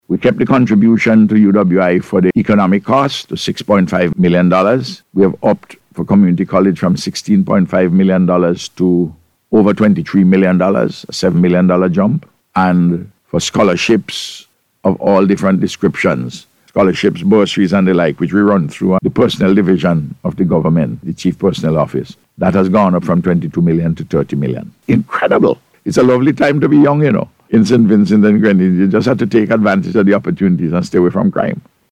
He made the comment during today’s Face to Face programme aired on NBC Radio.